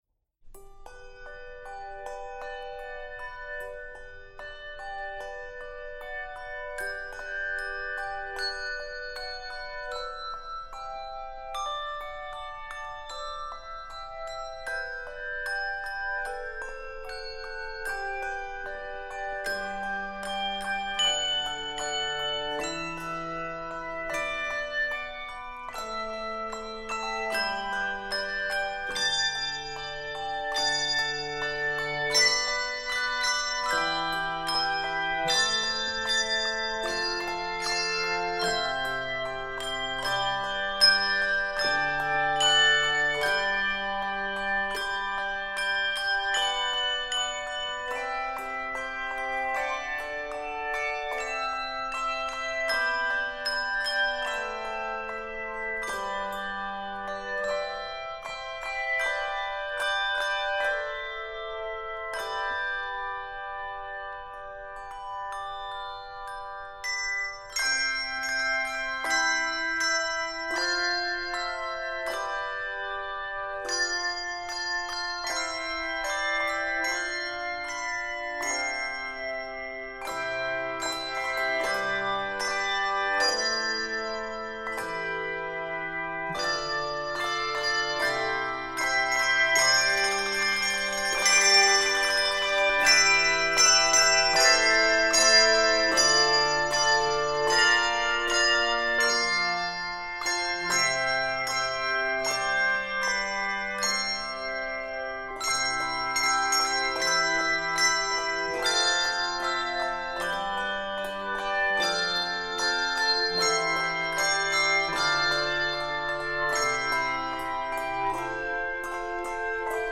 Keys of G Major and C Major.